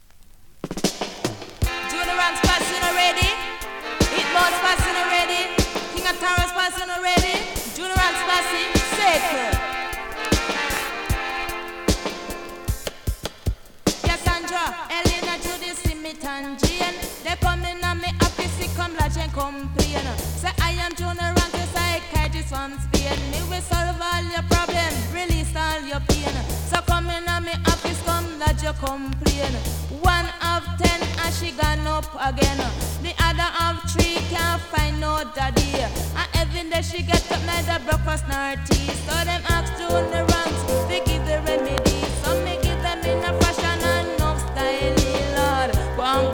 SKA〜REGGAE
スリキズ、ノイズかなり少なめの